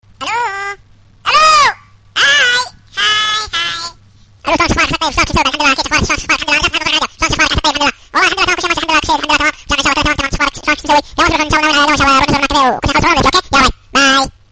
a-funny-hello_3548.mp3